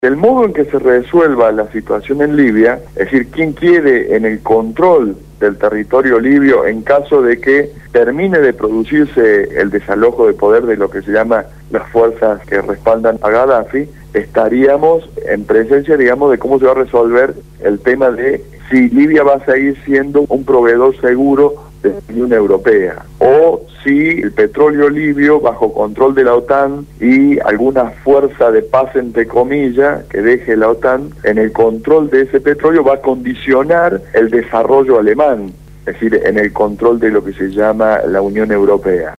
fue entrevistado en el programa «Punto de Partida» (Lunes a viernes de 7 a 9 de la mañana) de Radio Gráfica FM 89.3